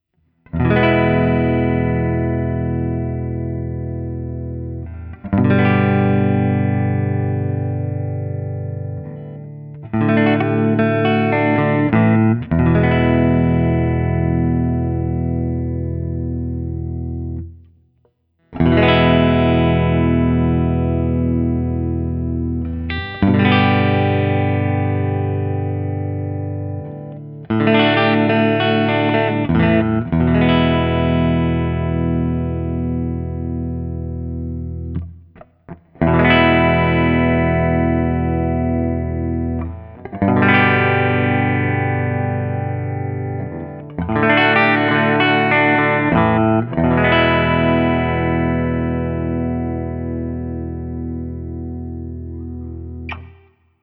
This is an aggressive sounding guitar that’s a bit dark for my tastes, a fact that I attribute to the Guild XR7 pickups and the mahogany body.
Open Chords #1
As usual, for these recordings I used my normal Axe-FX II XL+ setup through the QSC K12 speaker recorded direct into my Macbook Pro using Audacity.
For each recording I cycle through the neck pickup, both pickups, and finally the bridge pickup.